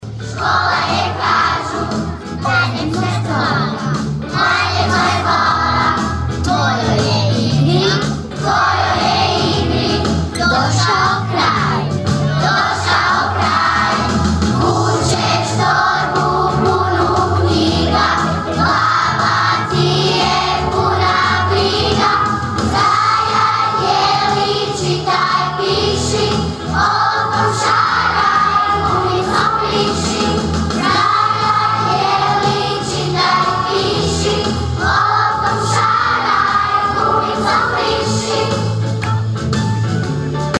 Prigodnom svečanošću danas je u Raši, prvog dana nove školske godine, otvorena obnovljena zgrada Osnovne škole Ivana Batelića: (
pjesma0909.mp3